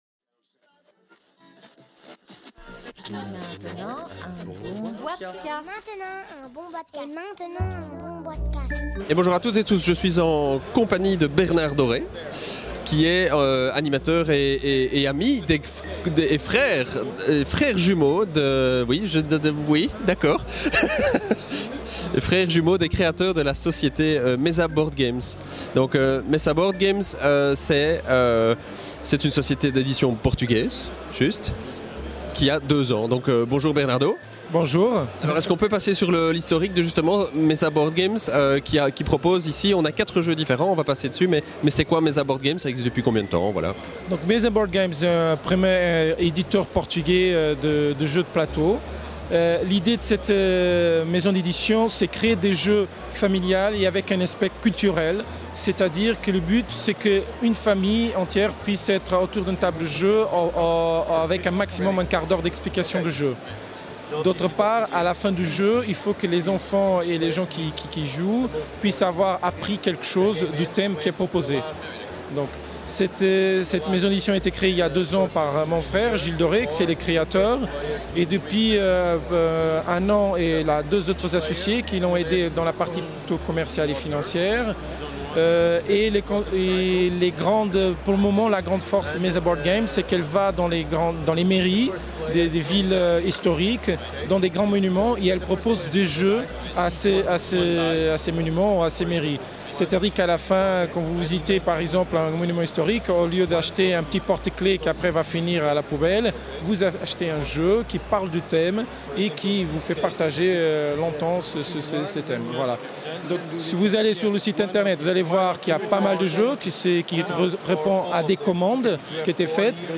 enregistré au Salon international du Jeu de Société de Essen – Octobre 2011